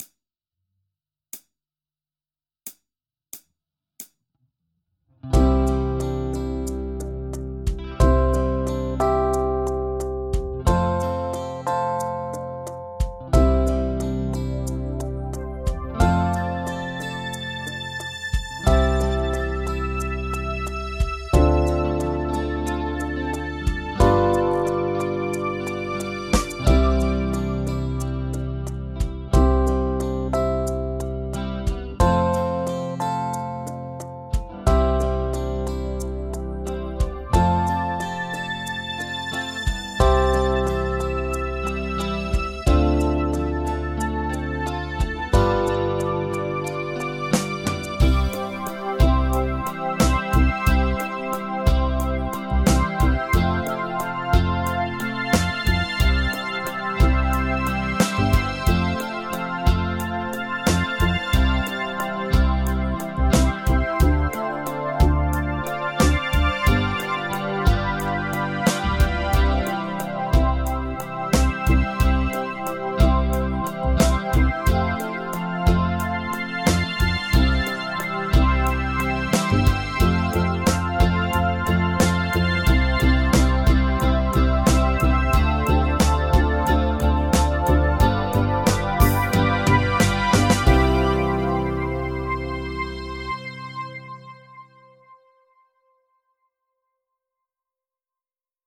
琉球スケール ギタースケールハンドブック -島村楽器